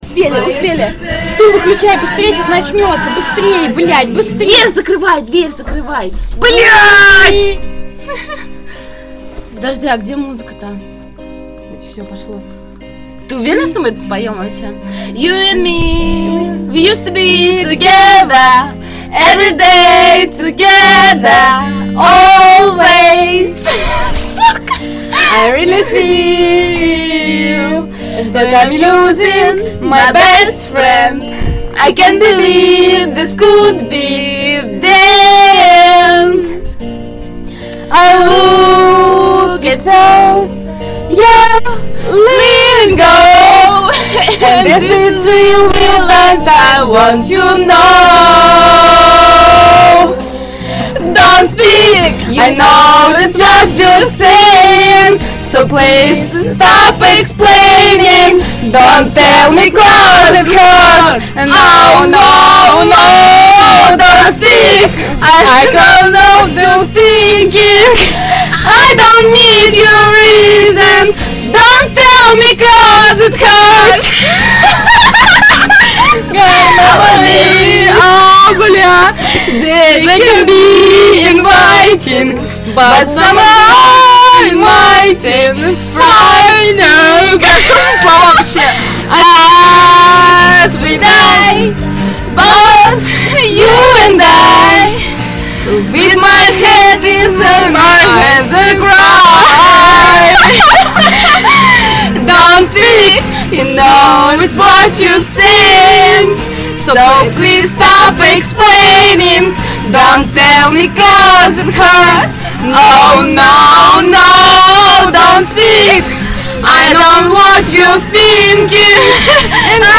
Кавер-версия